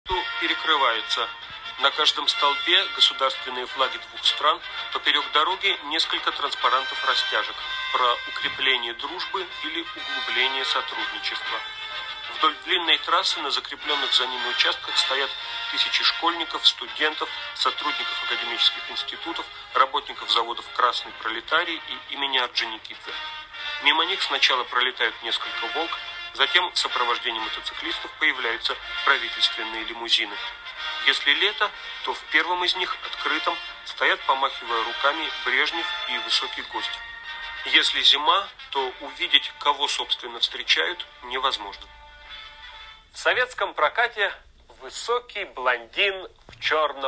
Возможно, аранжировка какой-то песни